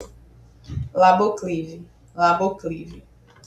TIPO DE EFEITO; Áudio de impacto sutil — destaque emocional na frase 'garanta o seu antes que acabe!'
TOM: Acolhedor, urgente sem ansiedade, empático
TRILHA: Instrumental suave — referência: piano + cordas leves
VOLUME TRILHA: Mixagem: fundo durante locução, sobe no início e fim
DESTAQUE: Ênfase natural em: 'duzentos kits' e 'garanta o seu'
RITMO: Fluido — sem pressa, mas com leve senso de urgência